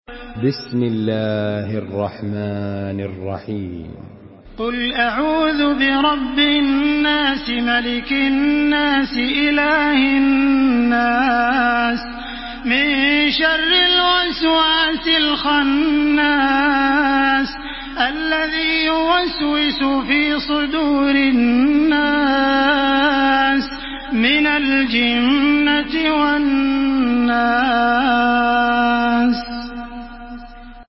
Surah Nas MP3 in the Voice of Makkah Taraweeh 1433 in Hafs Narration
Murattal